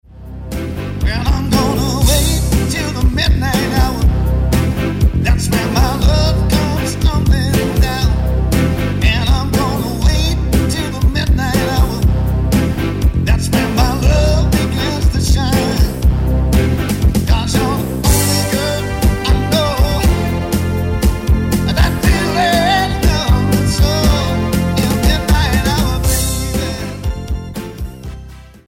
Singer saxophonist and entertainer.
Soul Music Samples